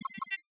NOTIFICATION_Digital_04_mono.wav